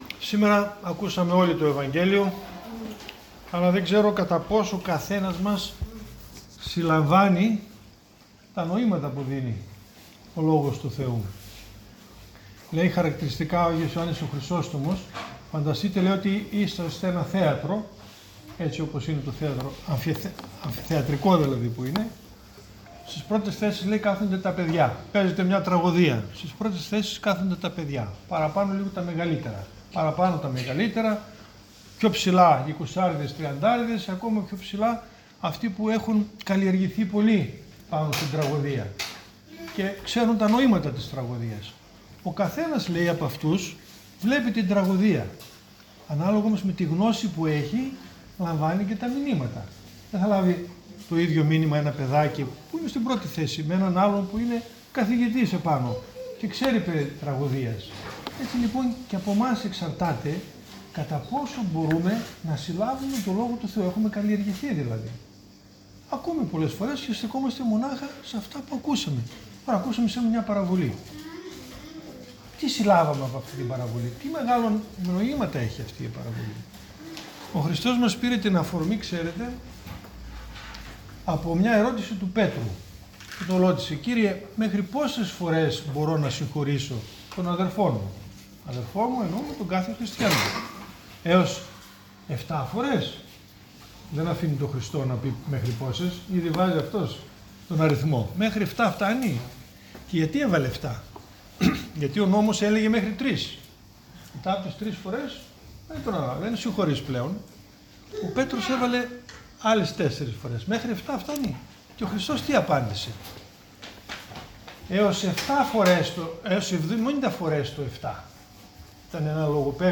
Ὁμιλία
στό Ἀρχονταρίκι τοῦ Μετοχίου